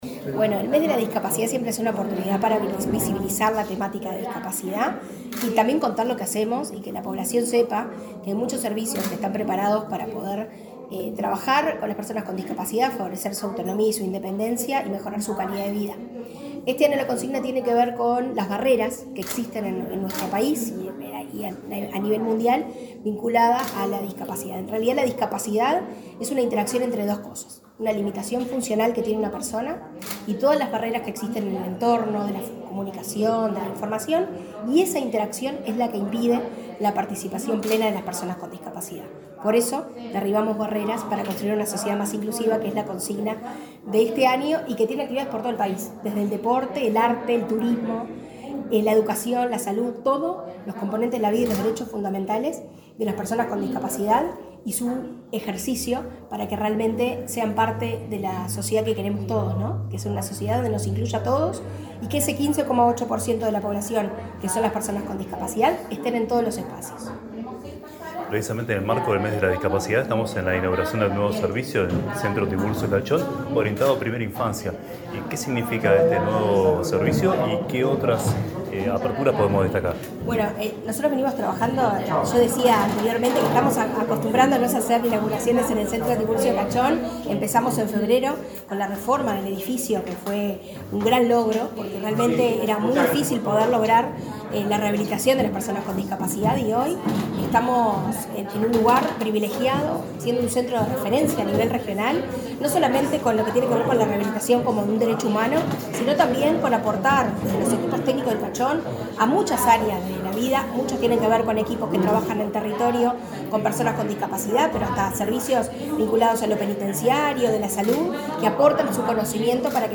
Entrevista a directora de Cuidados y Discapacidad, Karen Sass